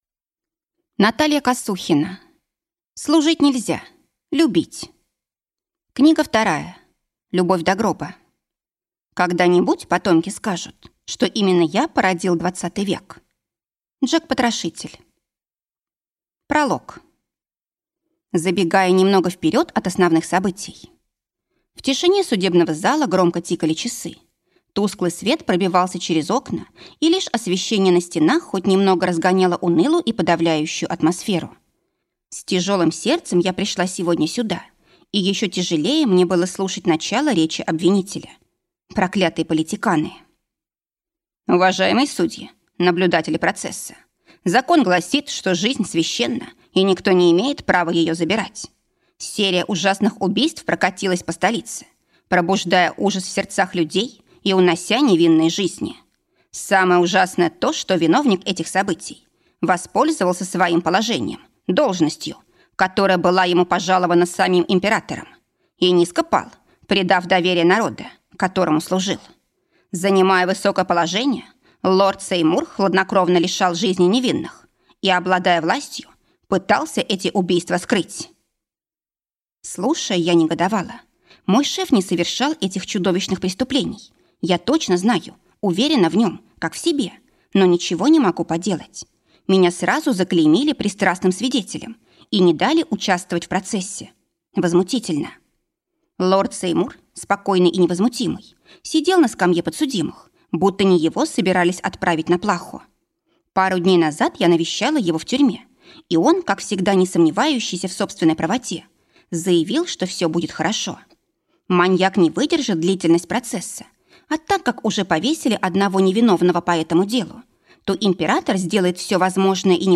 Аудиокнига Служить, нельзя любить! Любовь до гроба | Библиотека аудиокниг